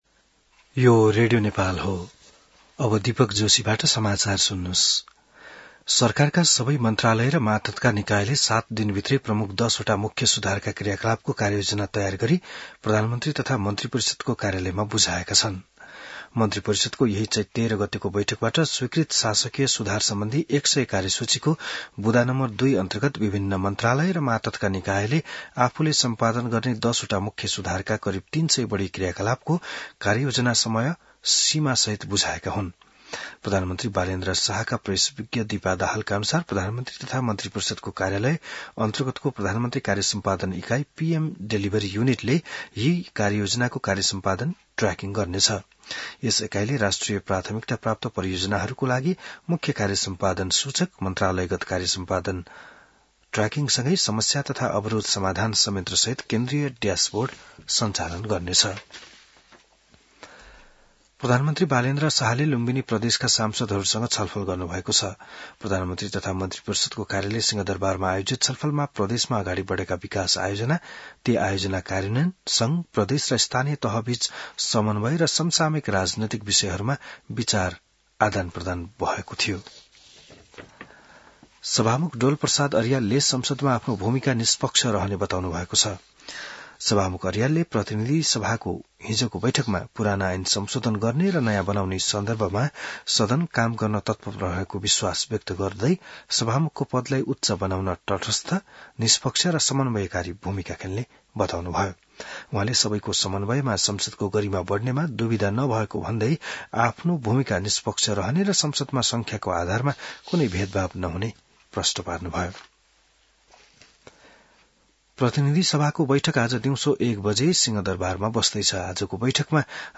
बिहान १० बजेको नेपाली समाचार : २४ चैत , २०८२